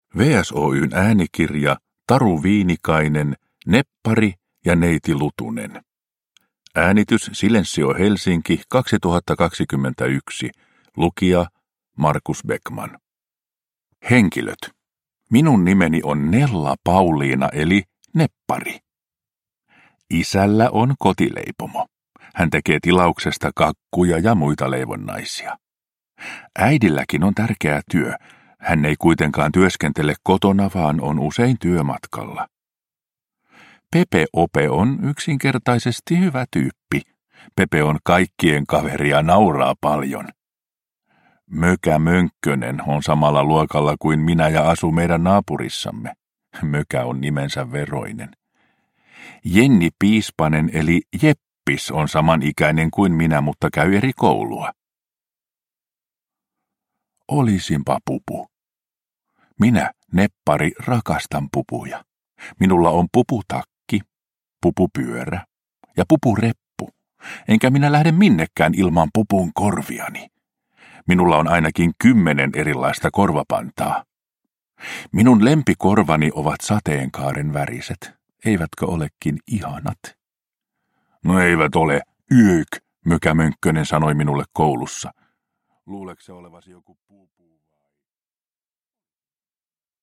Neppari ja Neiti Lutunen – Ljudbok – Laddas ner